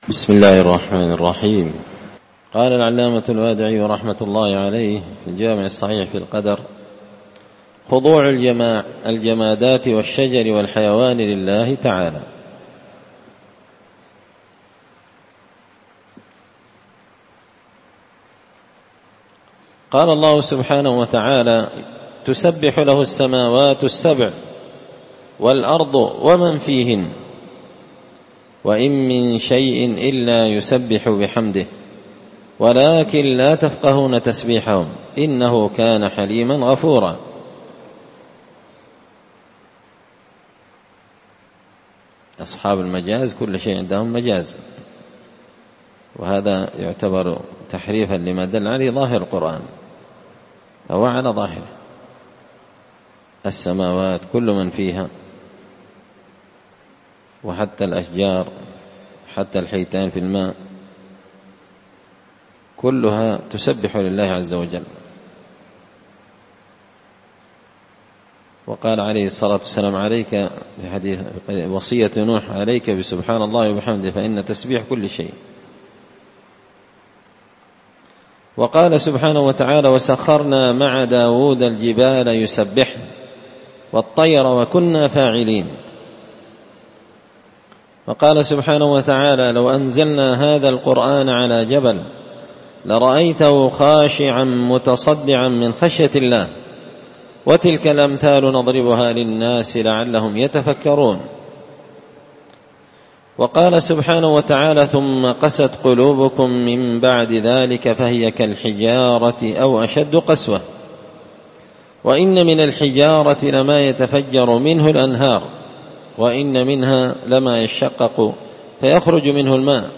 الدرس 115 فصل من عجائب قدرة الله الخارقة للعادة
دار الحديث بمسجد الفرقان ـ قشن ـ المهرة ـ اليمن